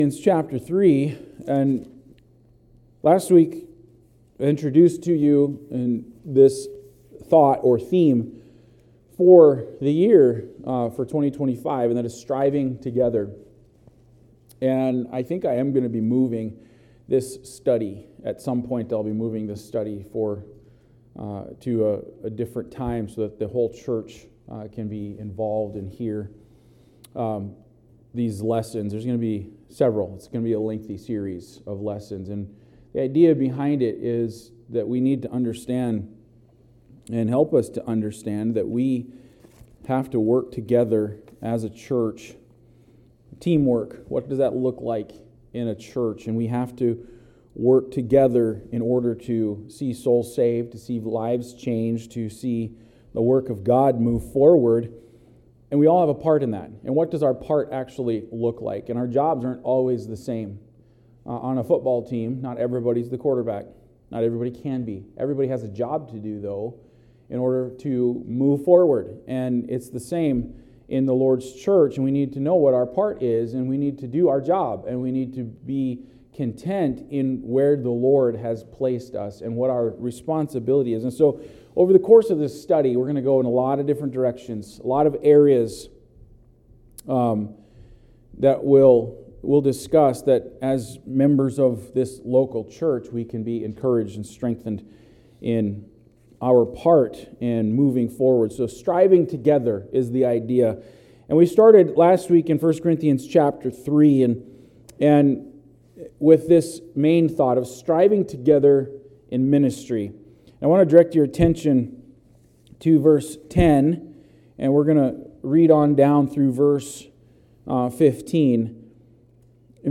Sermons | Plack Road Baptist Church podcast لمنحك أفضل تجربة ممكنة ، يستخدم هذا الموقع ملفات تعريف الارتباط.